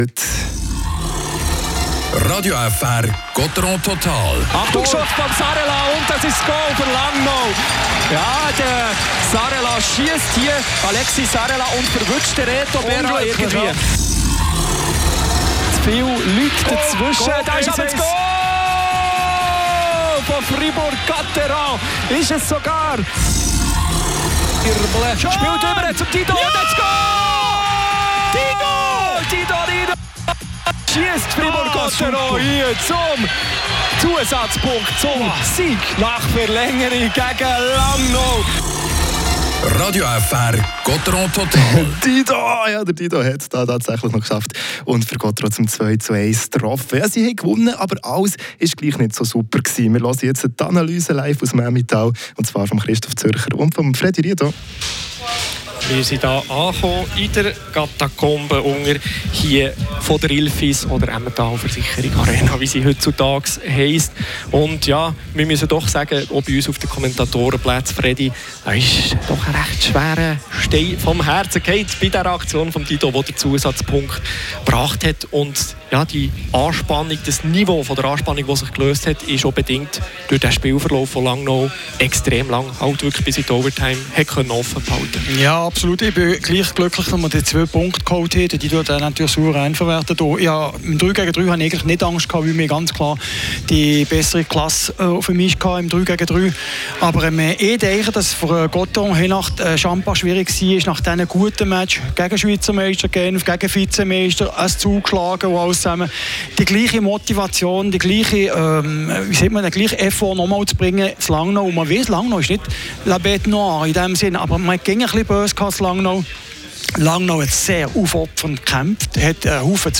Interview mit dem Spieler Raphael Diaz.